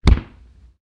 fall.ogg.mp3